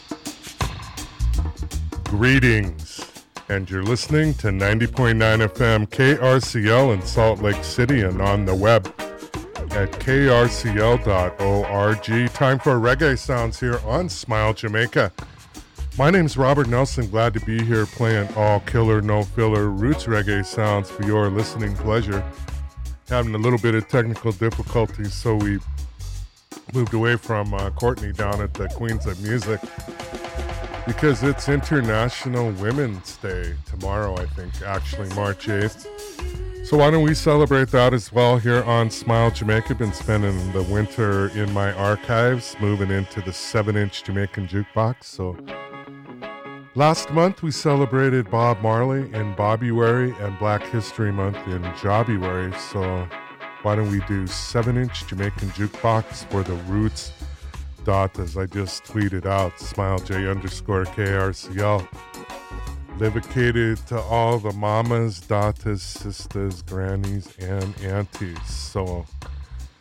Tag Archives: female reggae